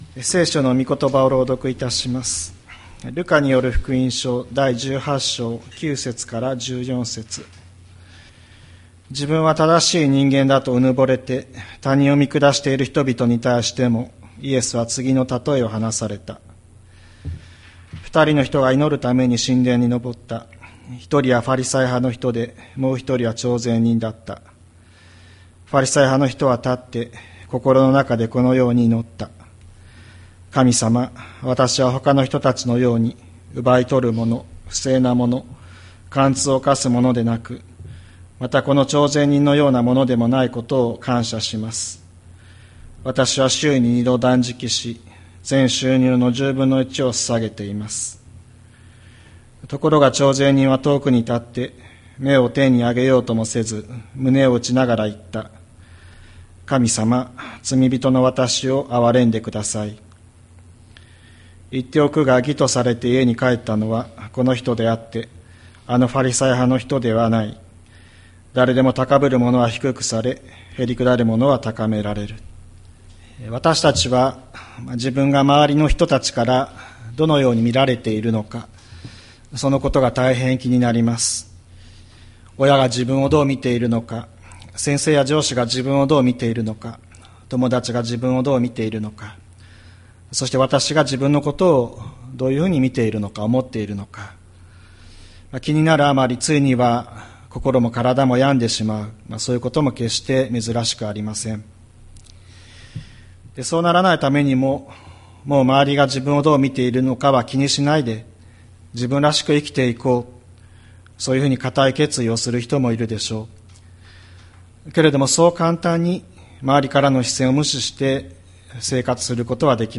2025年08月10日朝の礼拝「神のまなざしの中で」吹田市千里山のキリスト教会
千里山教会 2025年08月10日の礼拝メッセージ。